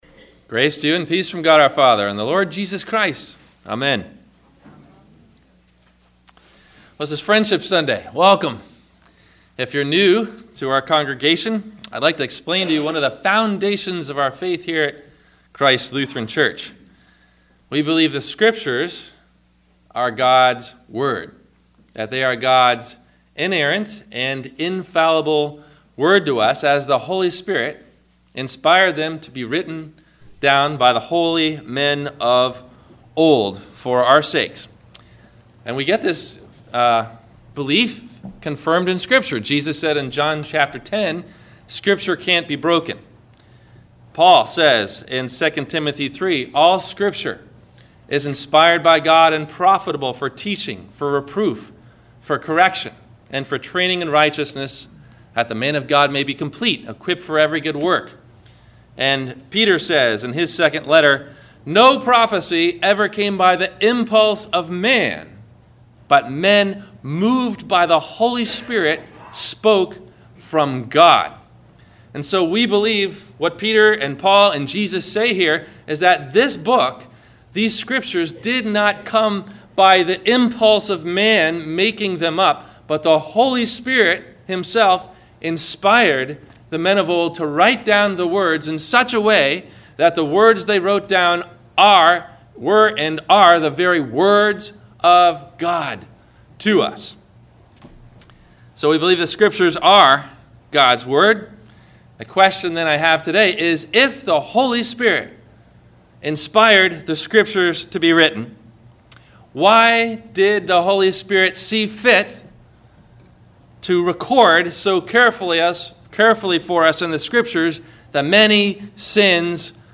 Gods Love For Sinners – Sermon – February 12 2012